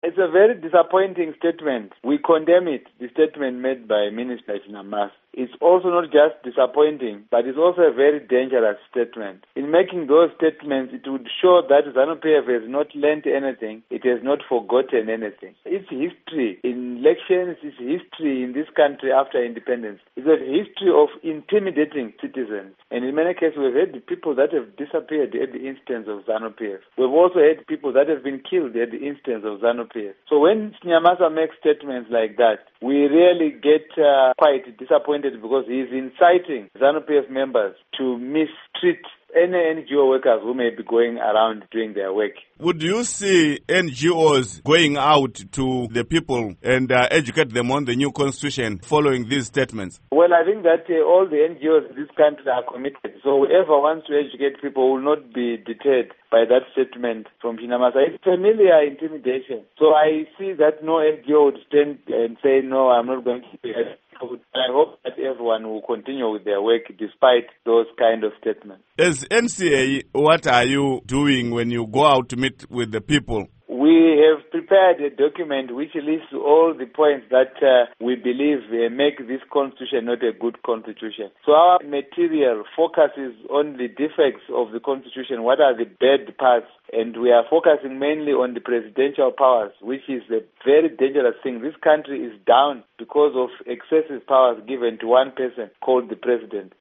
Interview With Lovemore Madhuku